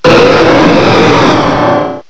sovereignx/sound/direct_sound_samples/cries/rayquaza_mega.aif at 5954d662a5762d73b073731aa1d46feab2481c5c